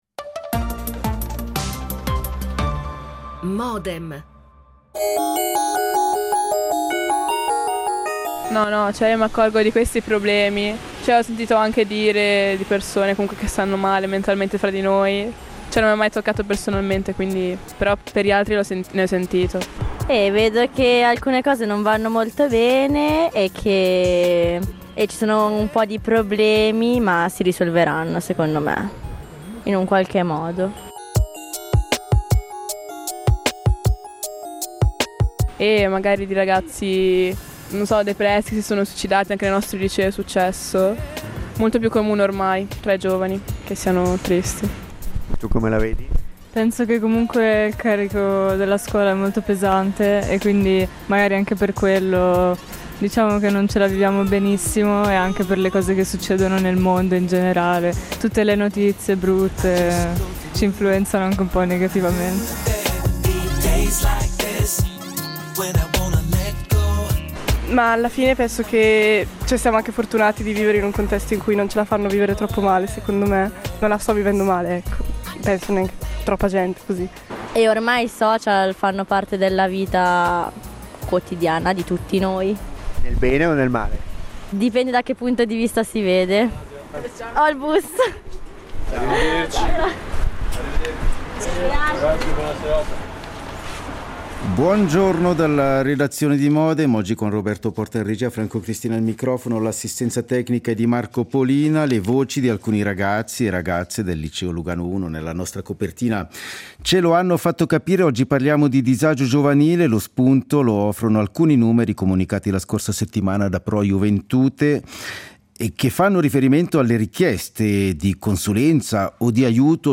Modem, appuntamento quotidiano (dal lunedì al venerdì) in onda dal 2000, dedicato ai principali temi d’attualità, che vengono analizzati, approfonditi e contestualizzati principalmente attraverso l’apporto ed il confronto di ospiti in diretta.